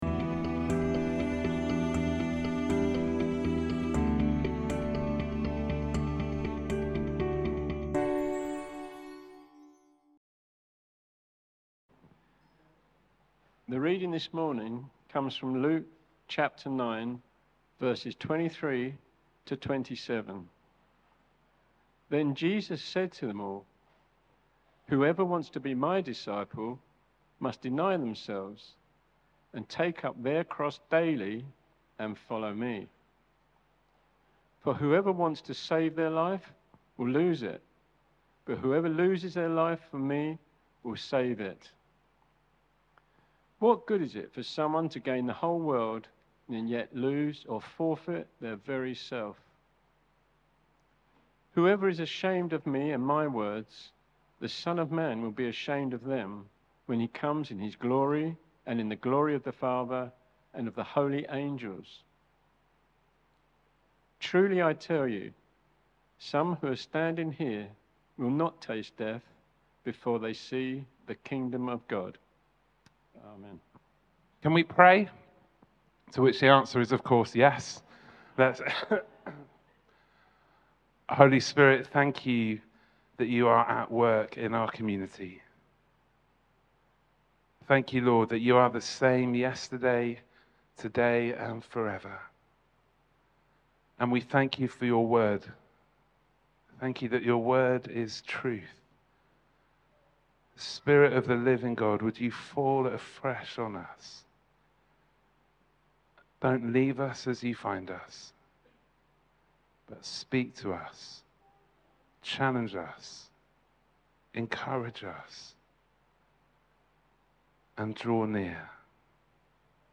2025-10-12 Sunday Talk – Worship 2